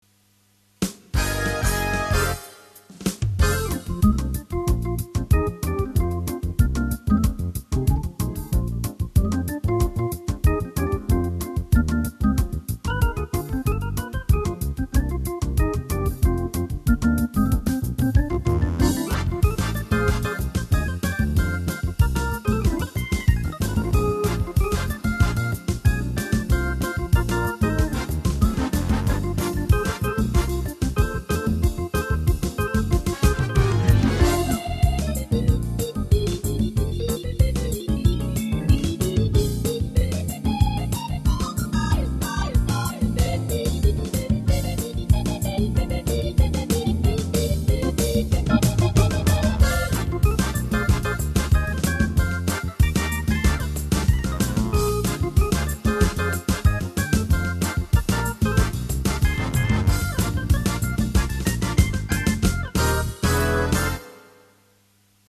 Dnes jsem mel trosku vic casu, takze jsem se vrhnul na tvorbu v oblasti jazzove..
A takhle si hraji moje klavesy beze me.. :o)
To nehraju já, ale můj syntezátor beze mě..